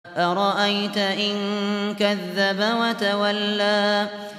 Hafız Abu Bakr al Shatri sesinden 96/ALAK-13 dinle!